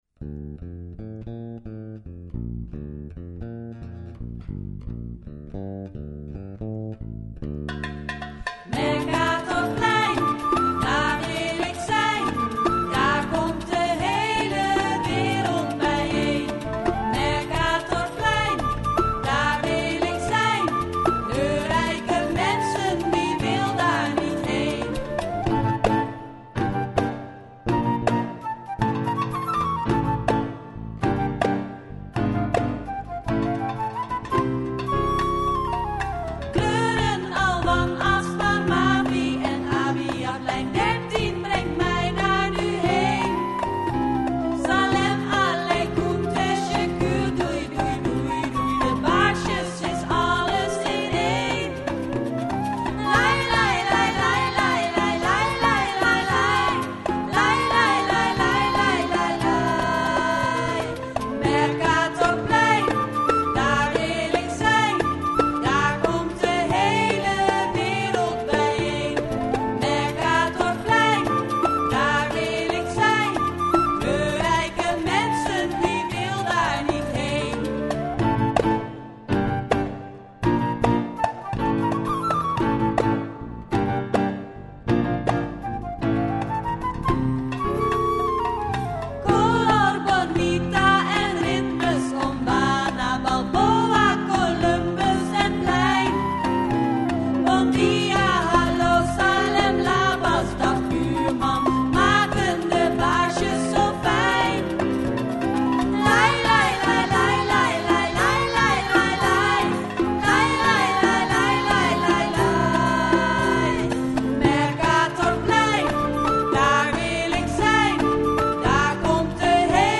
Een ode aan het Mercatorplein, gemaakt door de OMWaNA gelegenheidsformatie bestaande uit: